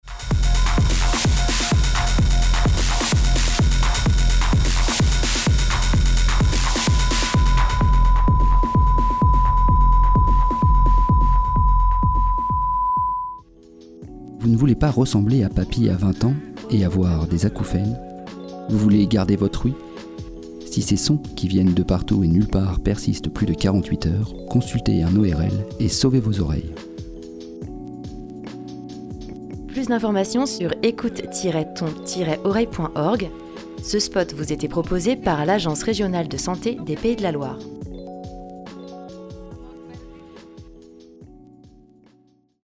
« [Musique techno en fond. Petit à petit un ultra-son s’ajoute à cette musique. L’auditeur n’entend que l’ultra-son qui fait office d’acouphène.]
Dose-sonore-spot-frap_01.mp3